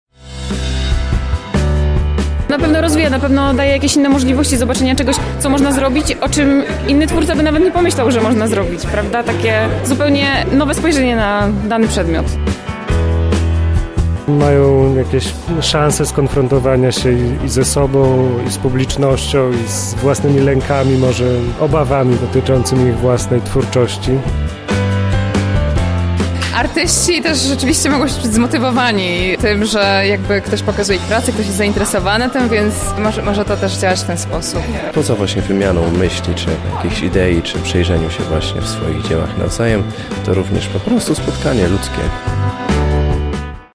Uczestnicy wczorajszego wernisażu ocenili, jakie szanse na to dają takie spotkania.